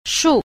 a. 術 – shù – thuật